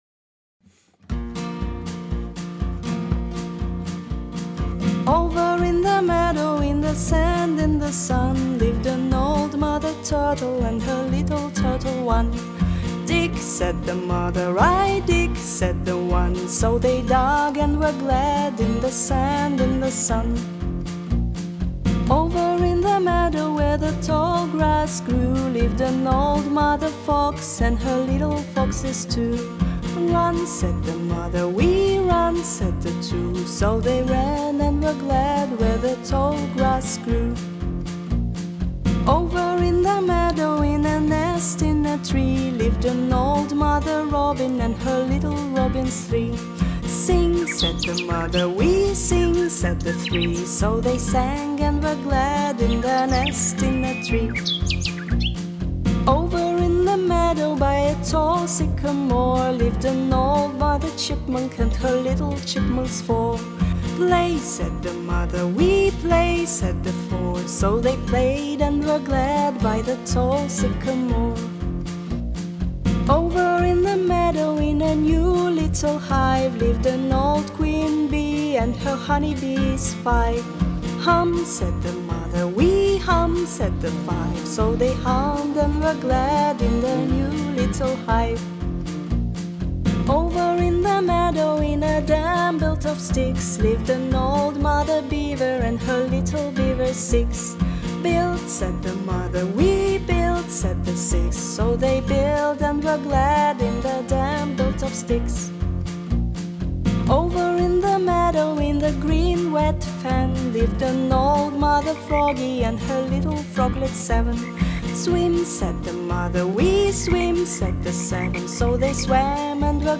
counting songs